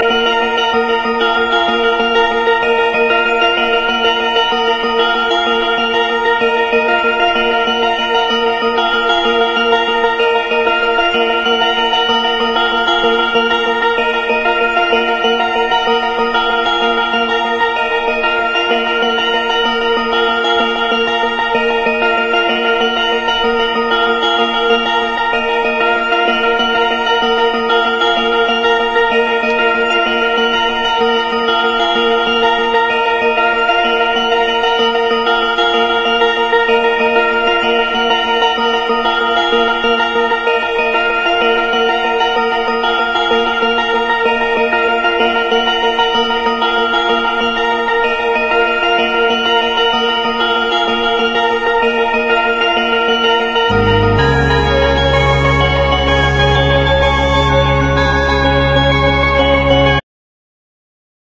狂気のミニマル電子音楽、最高傑作の呼び声も高い75年作品！
陰湿に繰り返されるヒプノティックなシーケンス・フレーズを軸にスペーシーな霧雨シンセが鮮やかに降り注ぐ